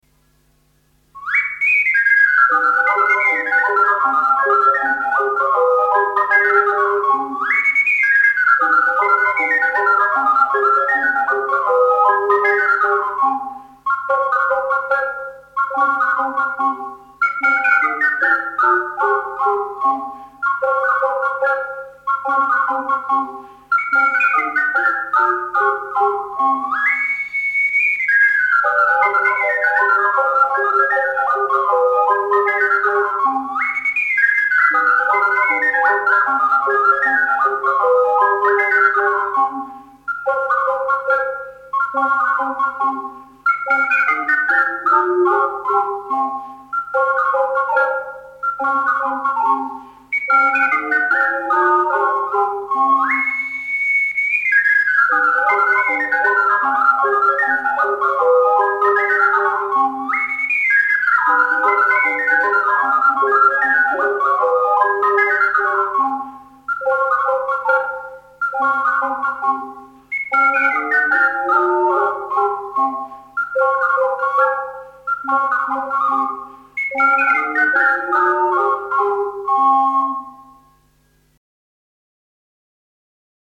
Balli popolari emiliani in .mp3
in incisione multipla con 5 ocarine